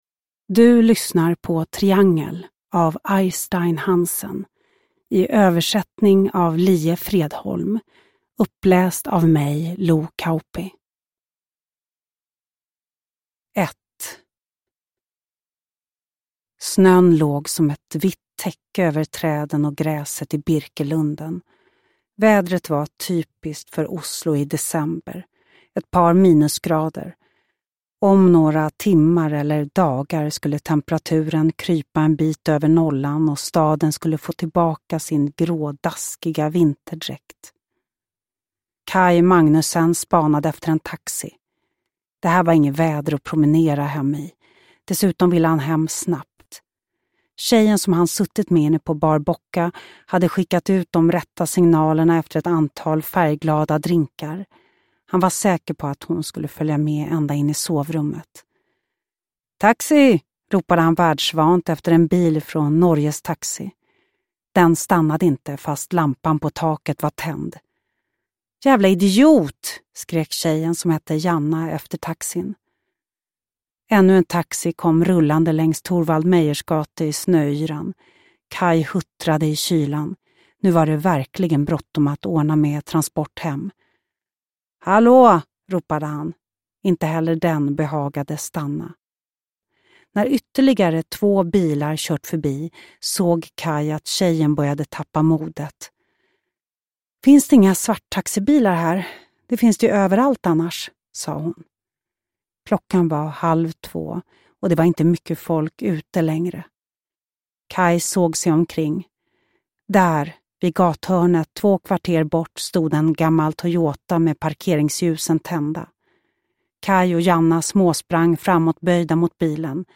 Uppläsare: Lo Kauppi